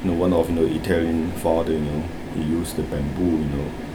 S1 = Taiwanese female S2 = Hong Kong male Context: S2 is talking about his experiences in a Catholic primary school, where some of the fathers were quite artistic.
Possibly, the vowel in the second syllable is not fully open, so it is heard as [e] rather than [æ] ; and the third syllable seems to be [lɪn] rather than [lɪən] .
Maybe the problem is that it was said quite fast and not very loudly.